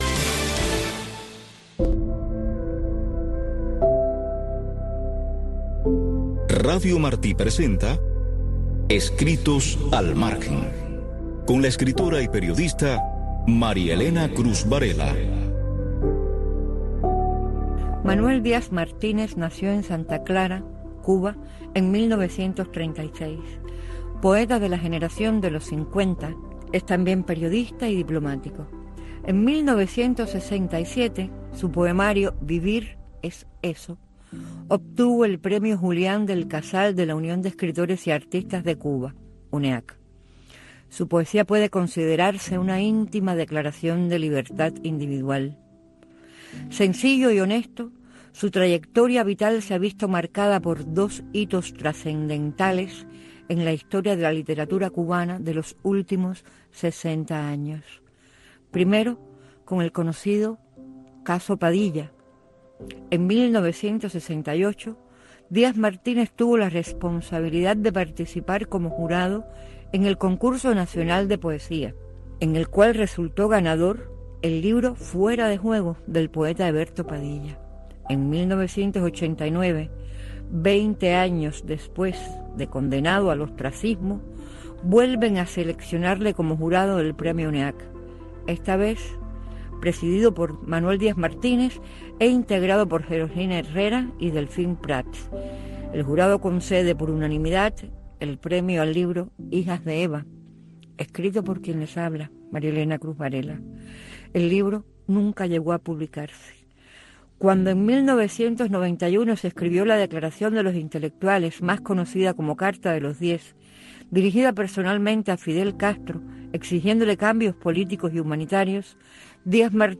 Revista informativa con noticias, eventos, blogs cubanos, efemérides, música y un resumen de lo más importante de la semana en el mundo del arte.